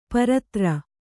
♪ paratra